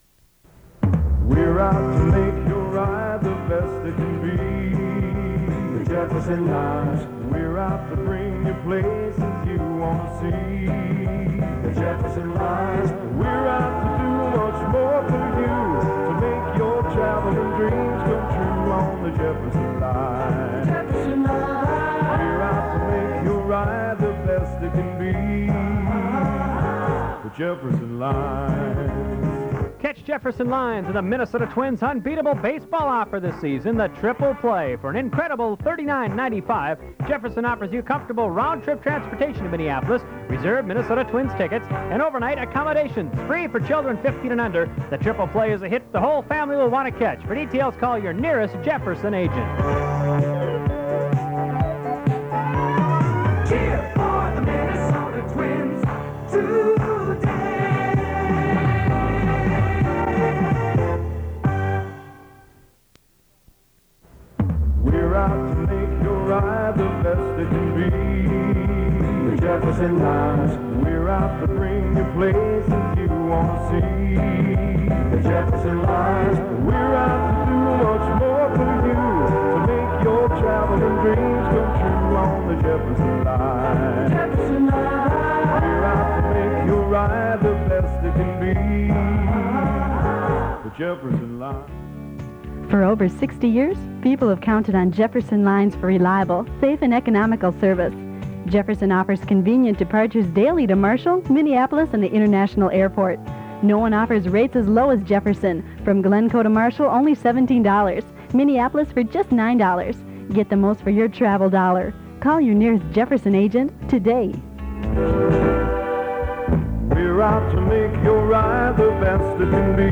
Jefferson Lines radio spot, 60 and 30 seconds, undated. 1 master audio file (3 minutes, 12 seconds): WAV (16.2 MB) and 1 user audio file: MP3 (2.9 MB).